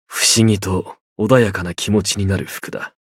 觉醒语音 是件穿起来心情不知为何会变得平静的衣服 不思議と穏やかな気持ちになる服だ 媒体文件:missionchara_voice_9.mp3